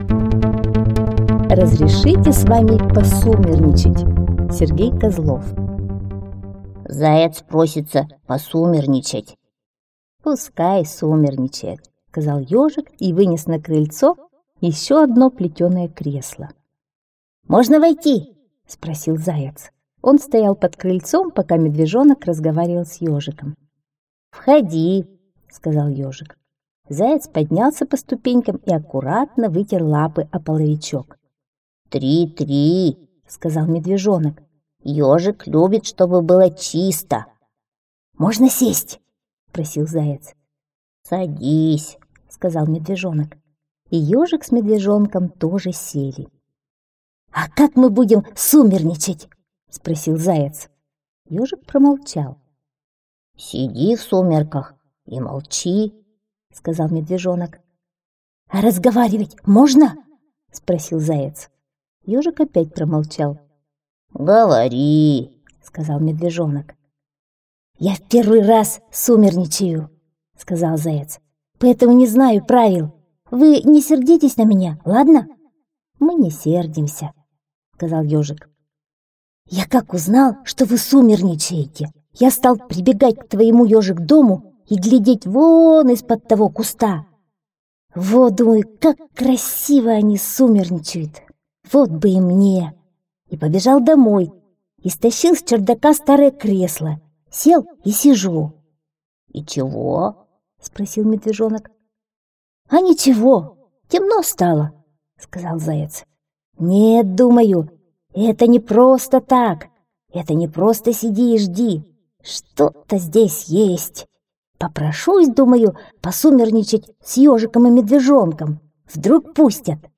Разрешите с вами посумерничать - аудиосказка Сергея Козлова